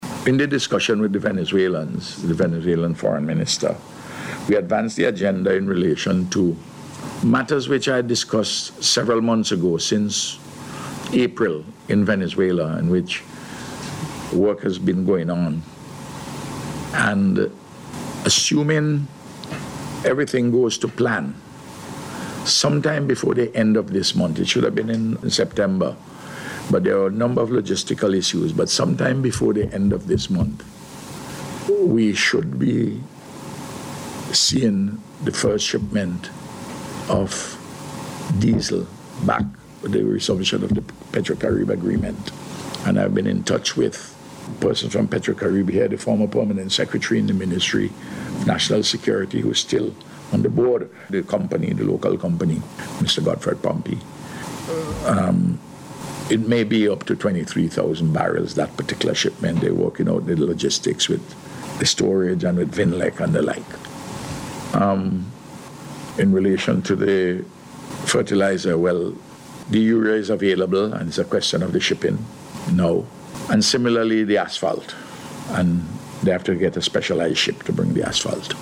This was disclosed by Prime Minister Dr. Ralph Gonsalves at a Media Conference at Cabinet Room on Tuesday.